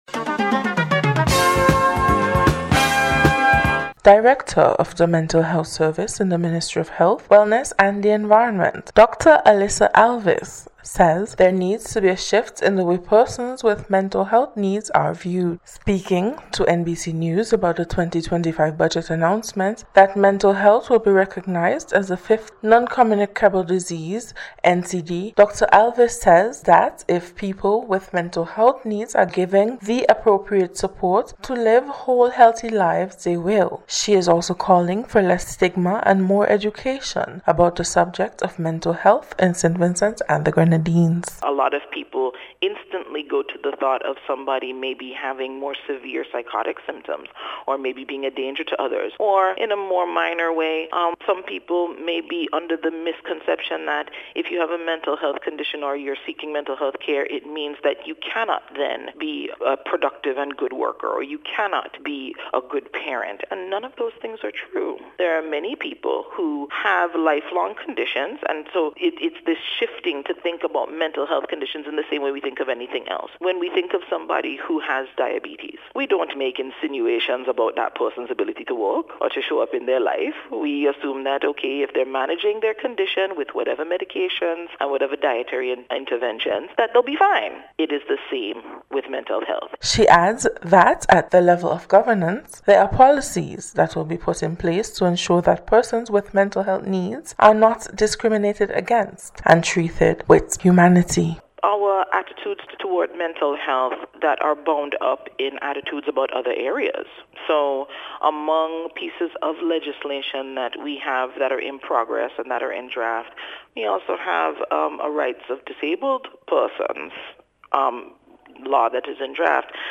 NBC’s Special Report- Thursday 23rd January,2025